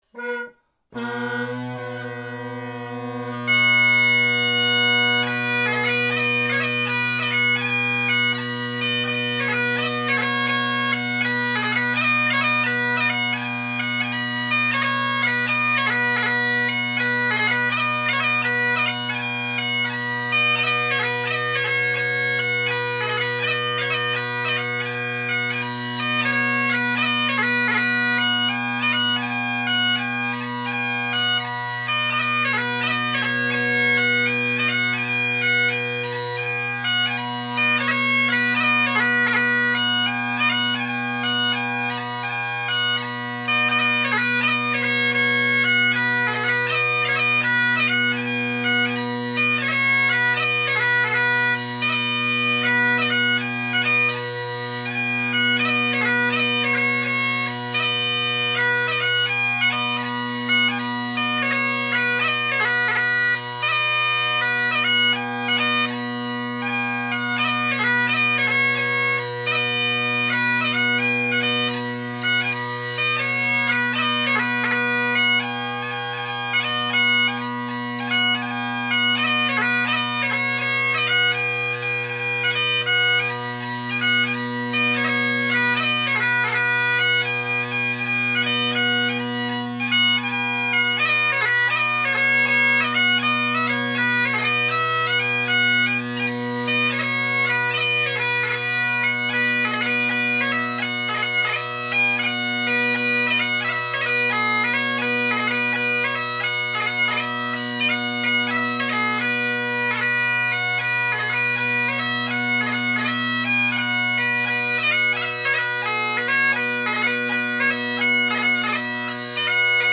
Great Highland Bagpipe Solo
Enough of that, I wanted to give some exposure to some Donald MacLeod 6/8 marches I never hear played and then I just kept flipping through the book and played some other tunes.